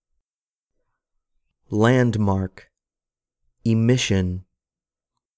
■ヒント：単語の読み上げ音声
１）land·mark（２） ＝　ター・タ
２）e·mis·sion（３） ＝　タ・ター・タ